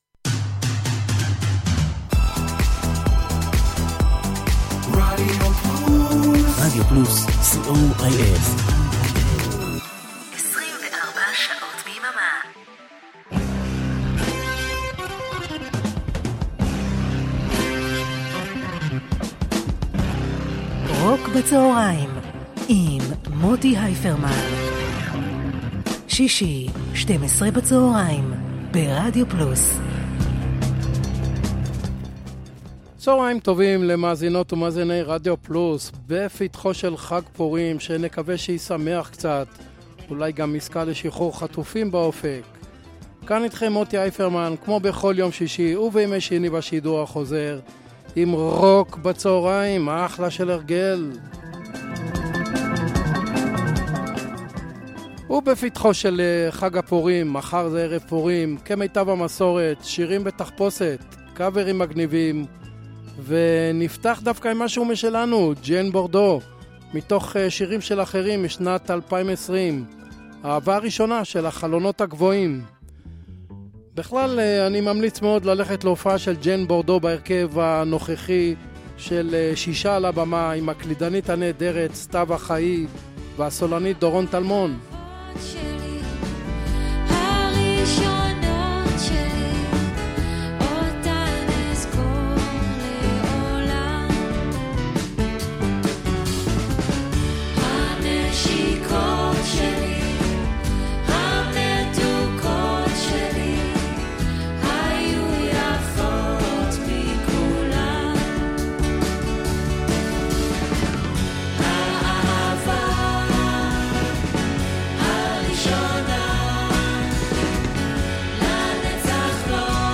blues rock classic rock
pop rock